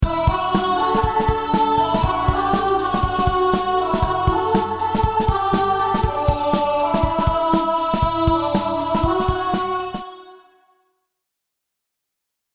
Vocals – may be too low for you to sing?
Same vocals, an octave higher